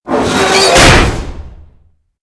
CHQ_VP_collapse.ogg